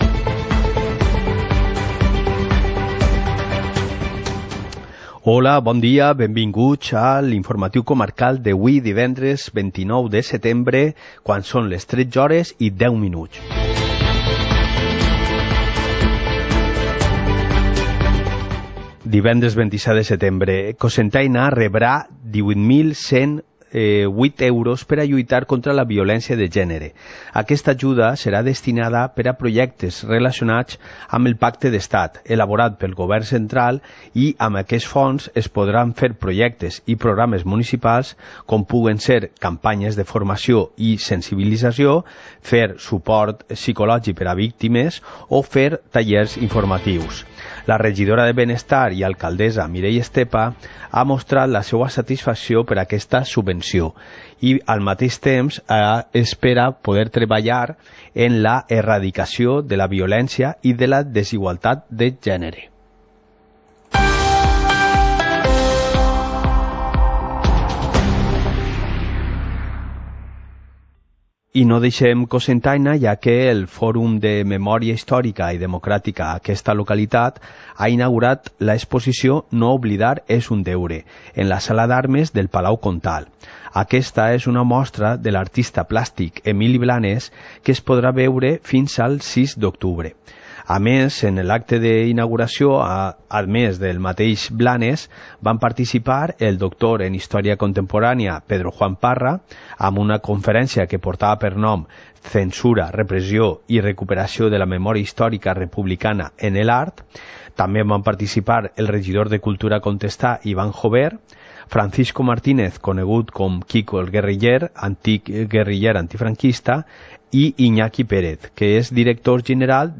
Informativo comarcal - viernes, 27 de septiembre de 2019